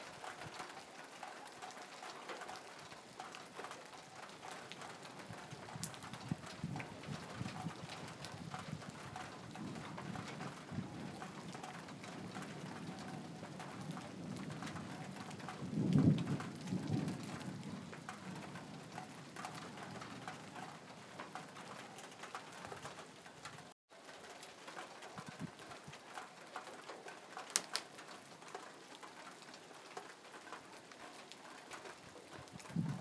слушаю дождь